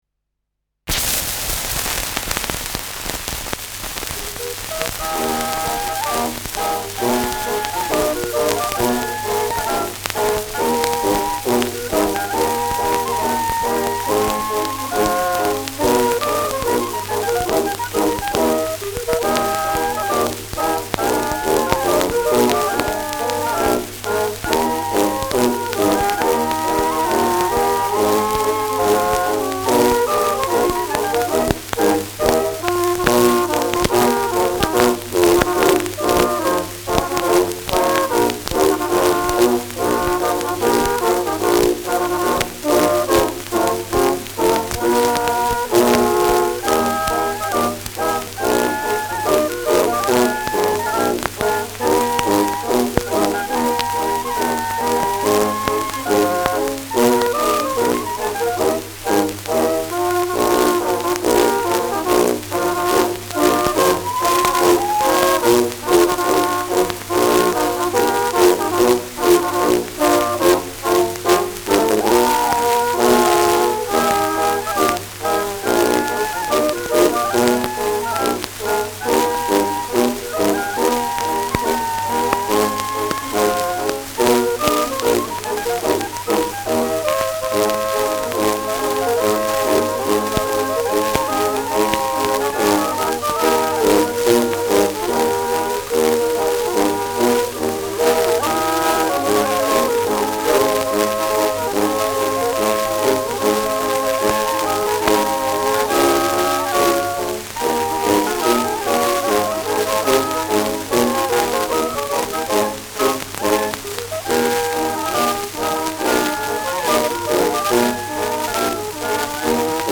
Schellackplatte
starkes Rauschen : leiert : starkes Knistern : abgespielt : häufiges Knacken
Das bekannte Volkslied ist hier im Trio zu hören.